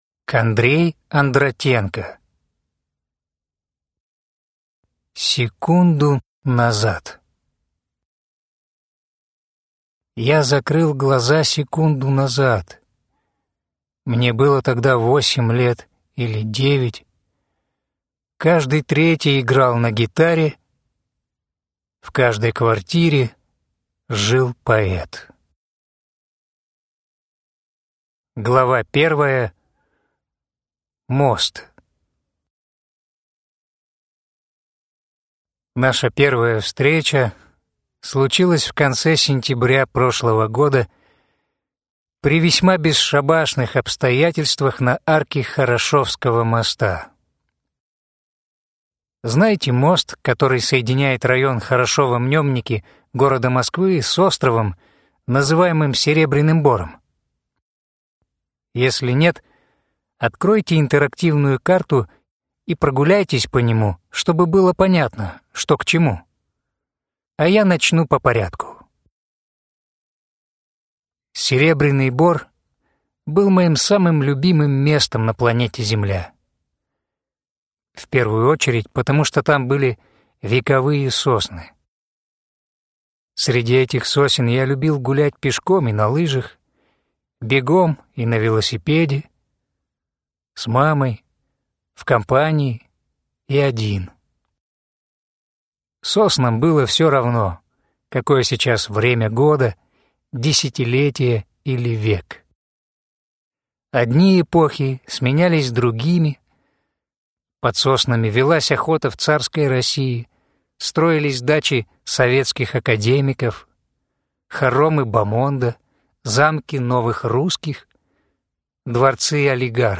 Аудиокнига Секунду назад | Библиотека аудиокниг